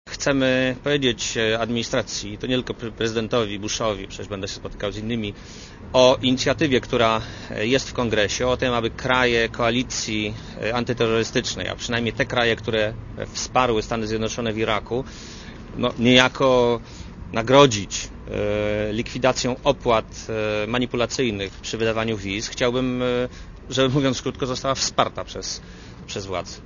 Posłuchaj komentarza Marka Belki
Oczywiście potwierdzę zainteresowanie naszego kraju dalszymi ułatwieniami, czy wreszcie materializacją obietnic dotyczących ułatwień w podróżowaniu do Stanów Zjednoczonych - powiedział premier dziennikarzom na lotnisku Okęcie przed odlotem do USA.